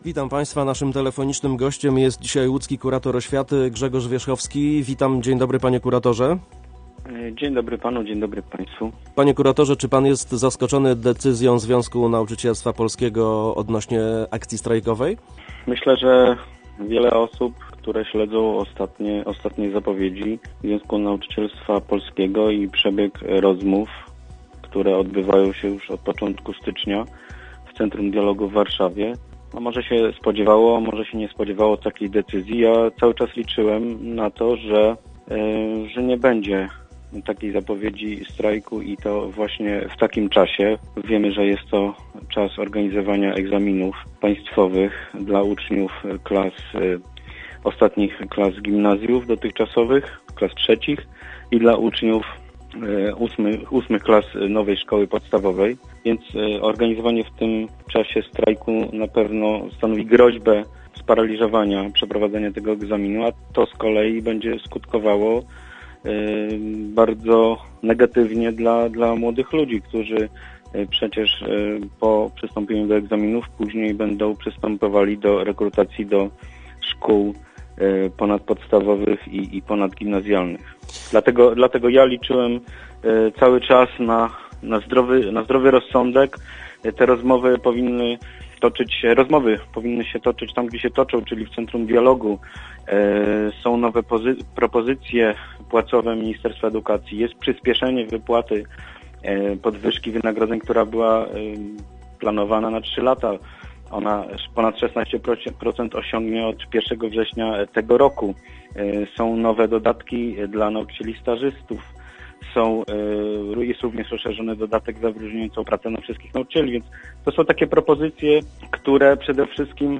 Gościem Radia Łódź był łódzki kurator oświaty Grzegorz Wierzchowski.